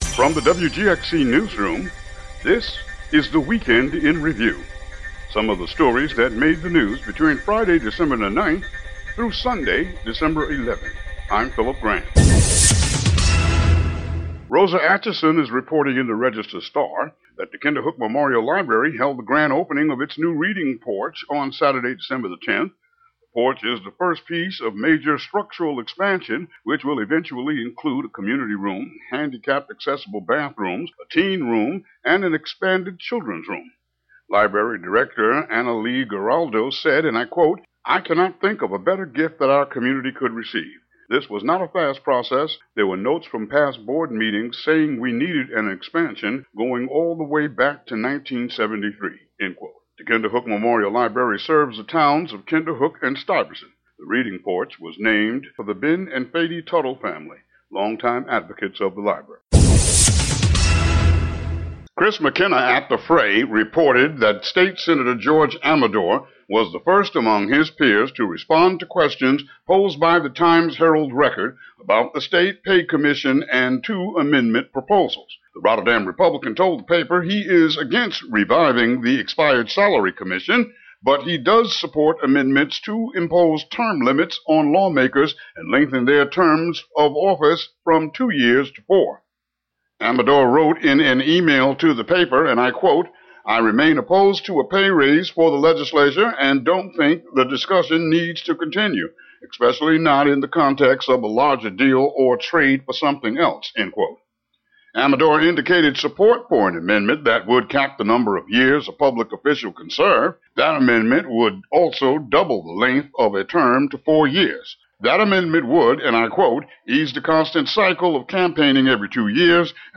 Daily local news for WGXC.